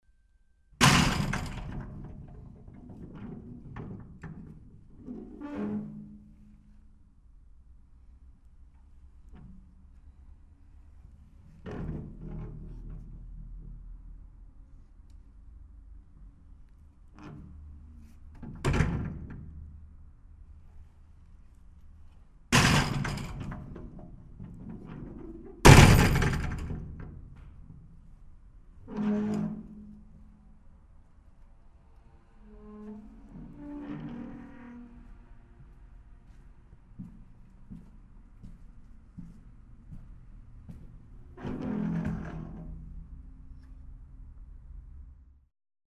Il consiglio è sempre lo stesso: ASCOLTATE SOLO CON LE CUFFIE altrimenti non potrete cogliere gli effetti spaziali
Sedie e porte